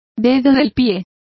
Complete with pronunciation of the translation of toe.